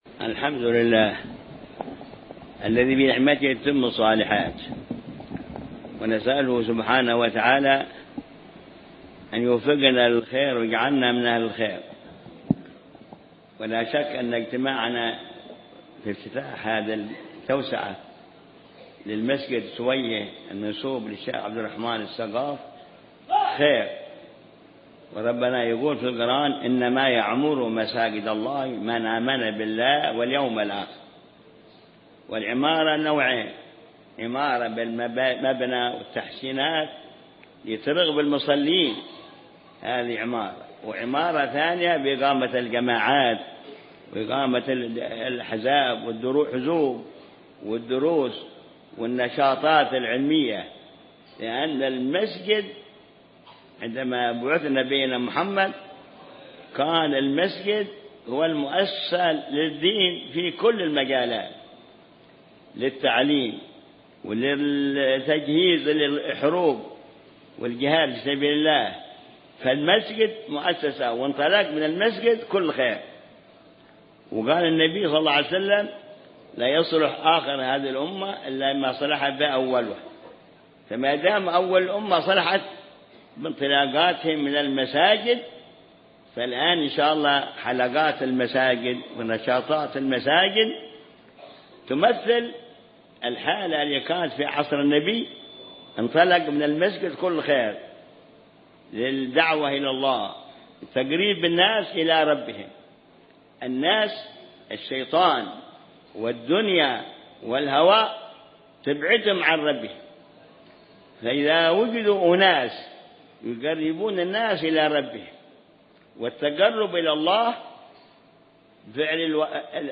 محاضرة
في افتتاح توسعة مسجد الإمام عبدالرحمن السقاف (سويّه)